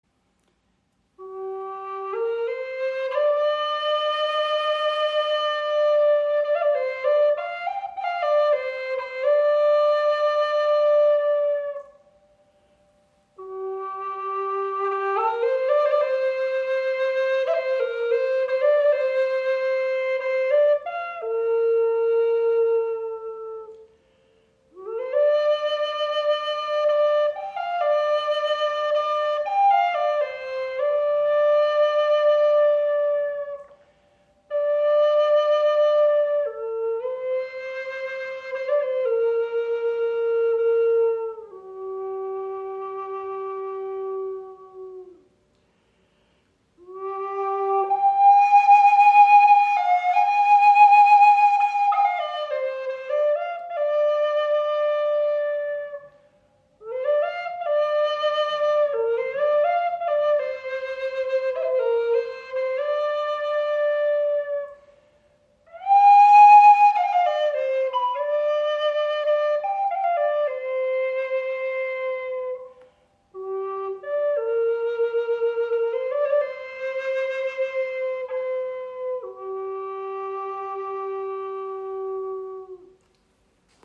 • Icon Handgefertigt aus Curly Shedua mit Cocobolo Puma-Windblock
• Icon Gesamtlänge 71  cm, 19  mm Innenbohrung – entschiedener Klang
In G-Moll gestimmt, entfaltet die Flöte einen klaren, lebendigen und präsenten Klang. Der Ton ist offen, tragfähig und nuanciert – ideal für Meditation, Rituale, Naturklang und freies, intuitives Spiel.